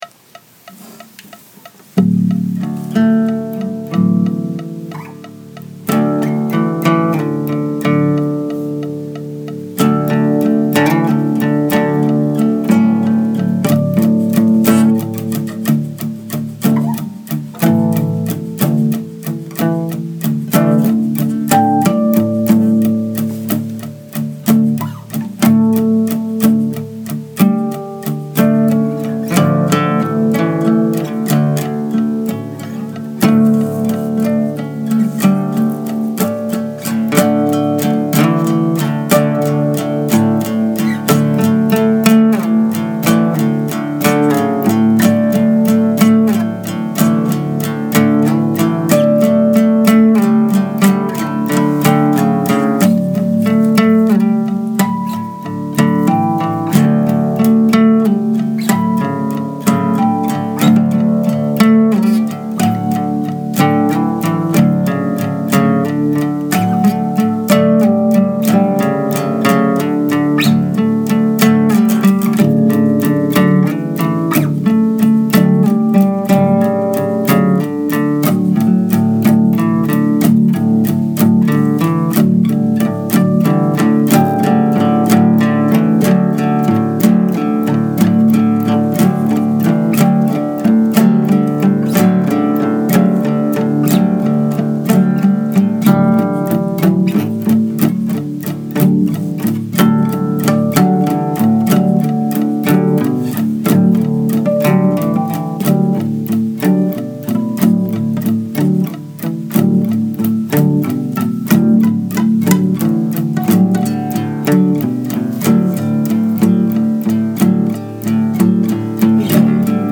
Do you want fries with that? 4 tracks on 4track iphone app. 94bpm […]